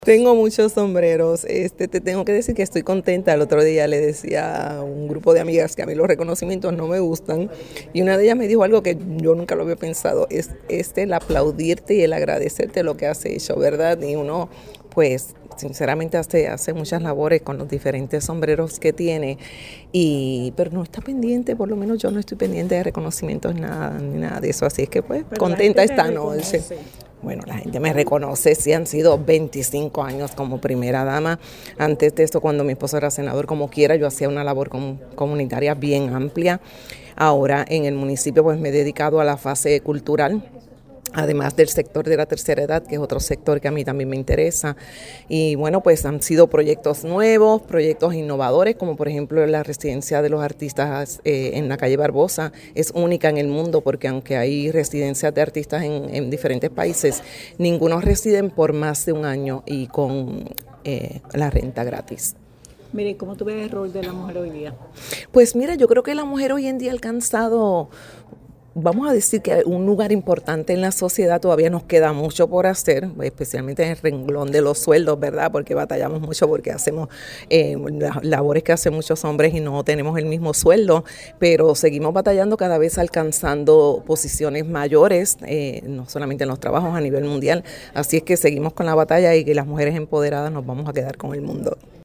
En el marco de la Semana de la Mujer la Legislatura Municipal de Bayamón rindió homenaje a cuatro mujeres cuya trayectoria refleja compromiso, liderazgo y vocación de servicio - Foro Noticioso Puerto Rico